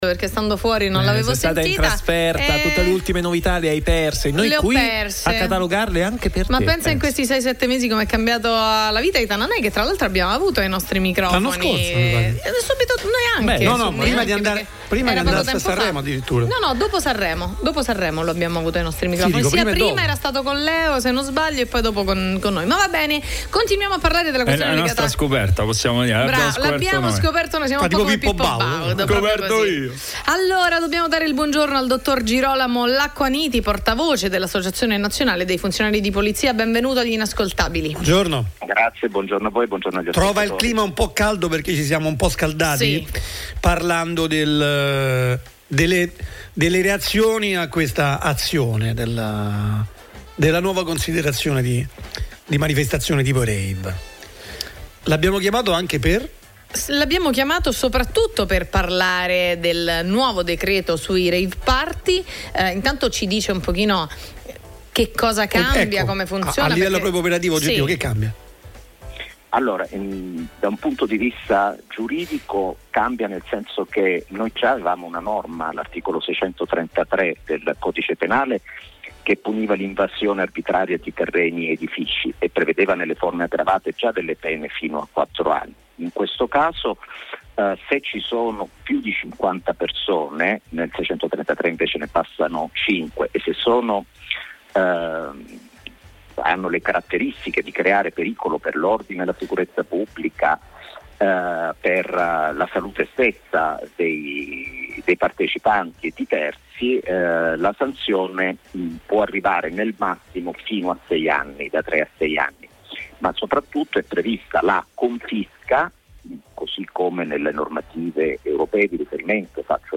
ASCOLTA L’INTERVISTA A NEW SOUND LEVEL